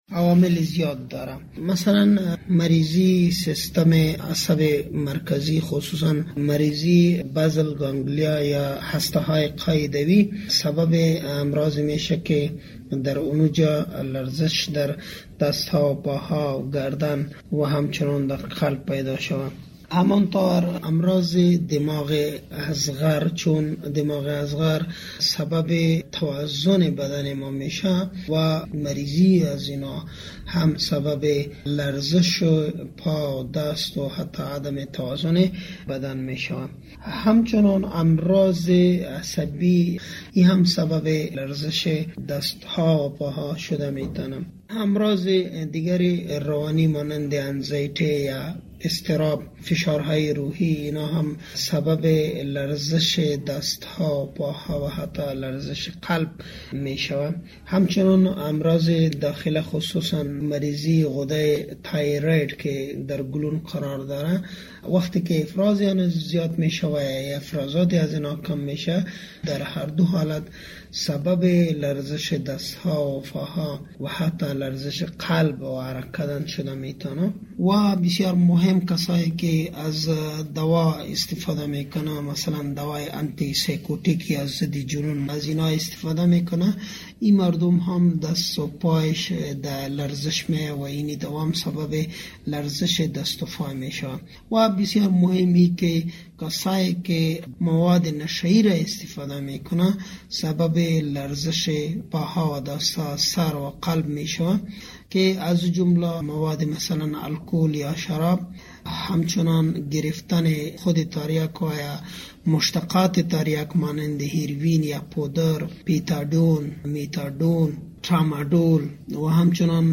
همکار ما در مصاحبه